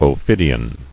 [o·phid·i·an]